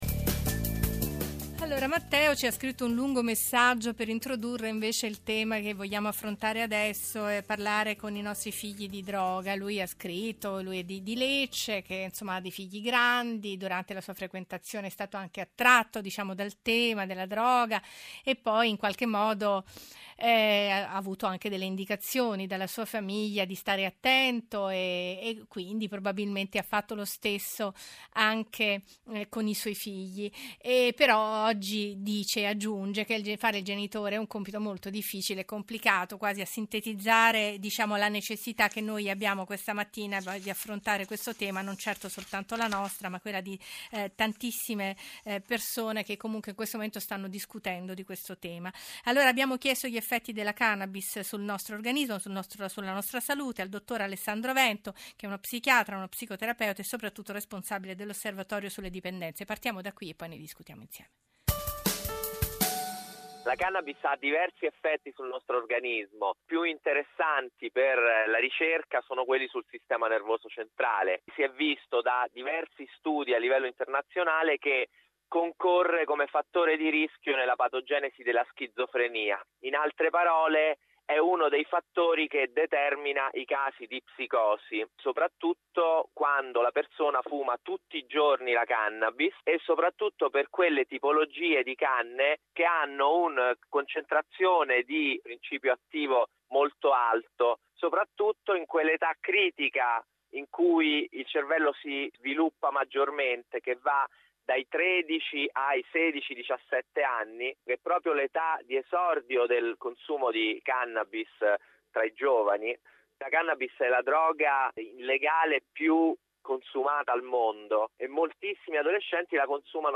trasmissione radiofonica Life Obiettivo Benessere andata in onda il 21/02/2017 su Radio RAI a proposito dell’uso della cannabis.